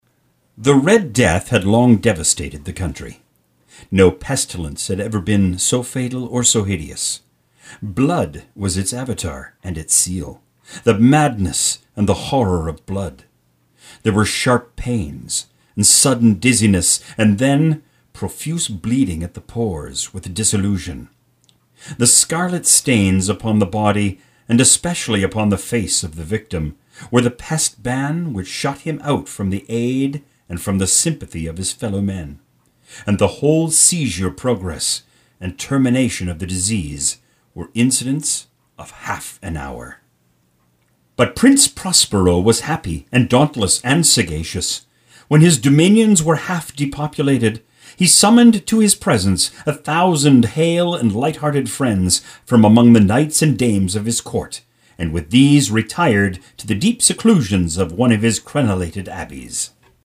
Mature Canadian Male Voice. Warm and engaging,or punchy and hard sell, adaptable for all situations.
Audio Book Voiceover